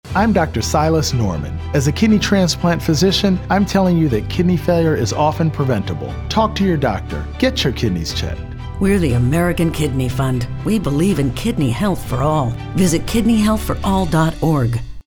Broadcast-quality PSAs available at no cost to your station.